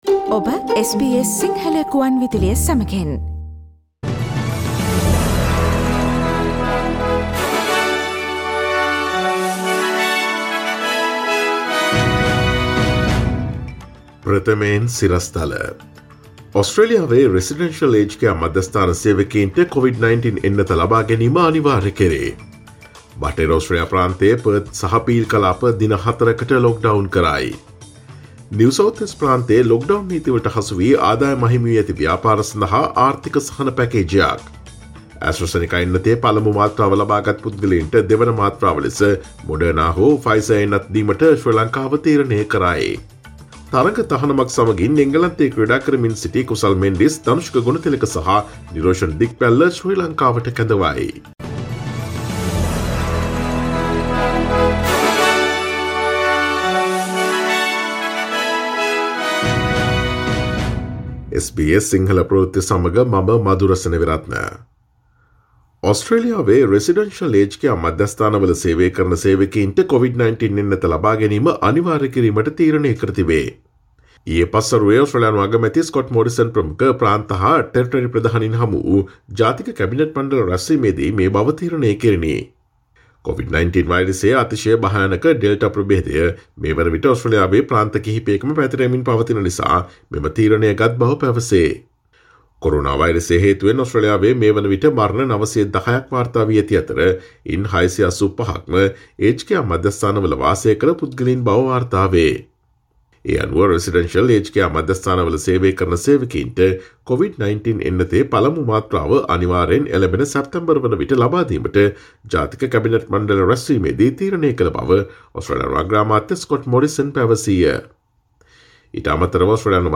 Here are the most prominent Australian, Sri Lankan, International, and Sports news highlights from SBS Sinhala radio daily news bulletin on Tuesday 29 June 2021.